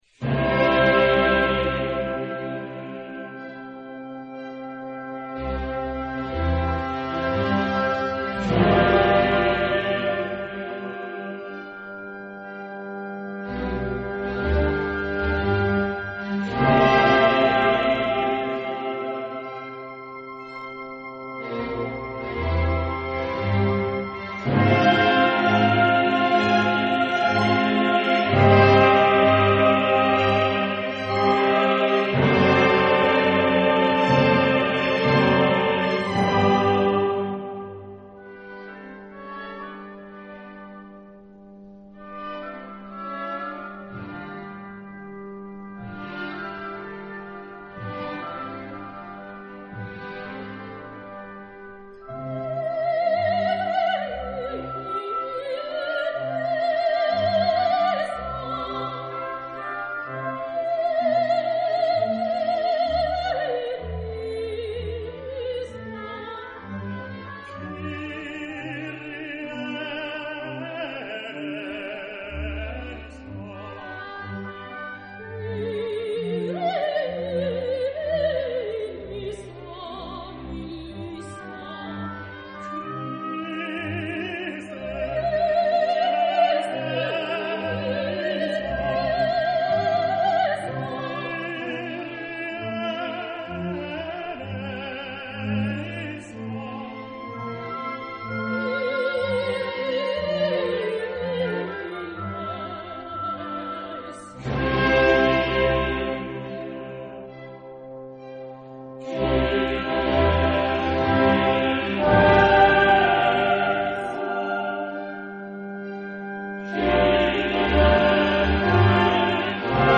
Genre-Style-Form: Sacred ; Mass
Type of Choir: SATB  (4 mixed voices )
Soloist(s): Soprano (1) / Alto (1) / Ténor (1) / Basse (1)  (4 soloist(s))
Instrumentation: Orchestra + Organ  (15 instrumental part(s))
Instruments: Oboe (2) ; Clarinet in C (2) ; Horn in C (2) ; Trombone (3) ; Timpani ; Violin (2) ; Double bass (1) ; Organ (1) ; Bassoon (1)
Tonality: C major